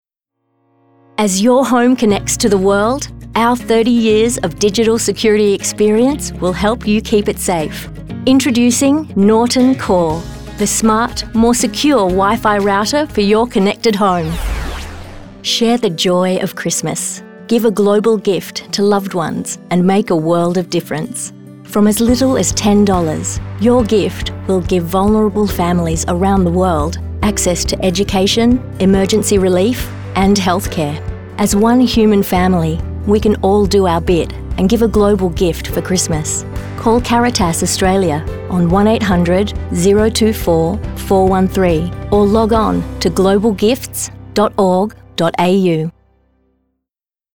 Female
English (Australian)
A bright, intelligent and natural voice with the ability to interpret nuance and complex characters.
Radio Commercials
Soft Read Samples
Words that describe my voice are bright, Natural, sincere.